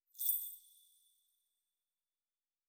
03_鬼差脚步_4.wav